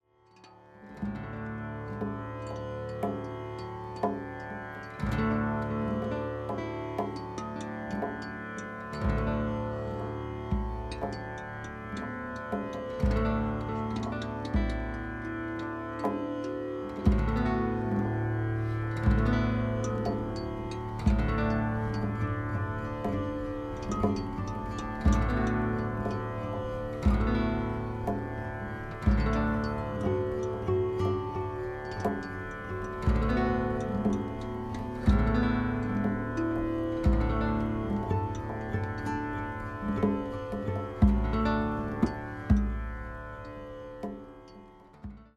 深みのあるギター・サウンド。囁くような歌声に呼び起こされるのはいつかの思い出。